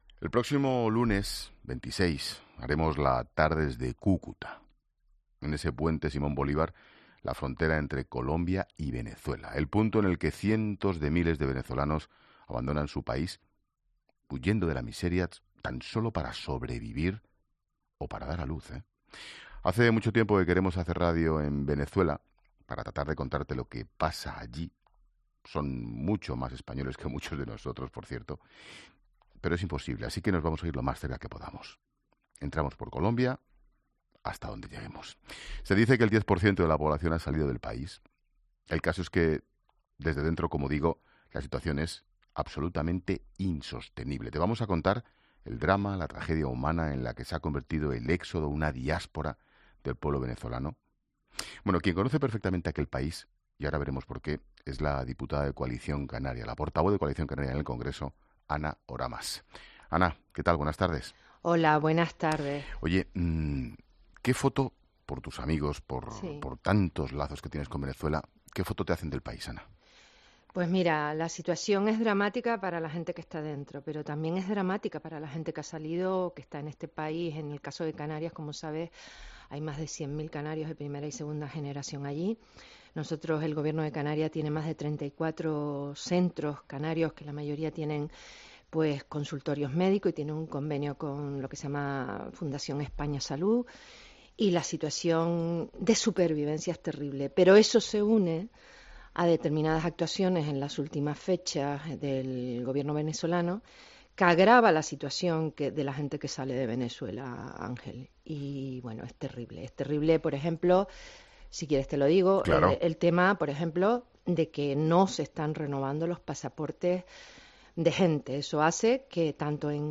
ESCUCHA LA ENTREVISTA COMPLETA | Ana Oramas en 'La Tarde'